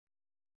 ♪ duṇḍuṛumbe